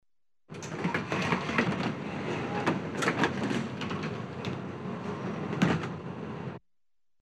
Трамвай старый: двери, открывание и закрывание
Тут вы можете прослушать онлайн и скачать бесплатно аудио запись из категории «Двери, окна».